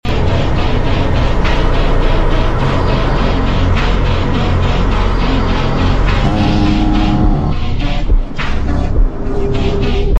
SOUND ON‼ F18 Spitting Bullets💯🤩🤙🏻 Sound Effects Free Download